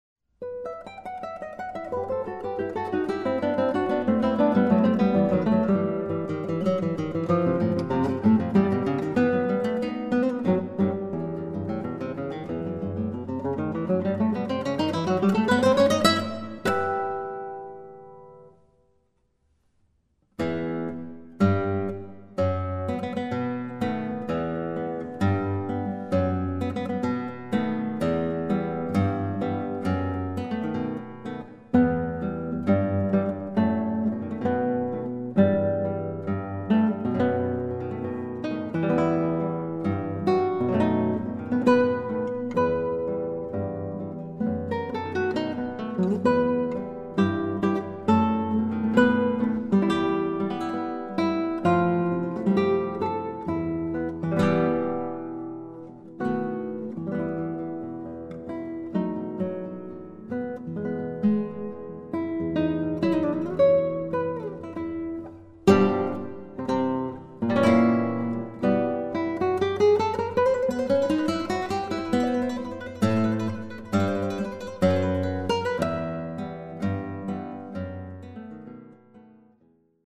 Guitar Duo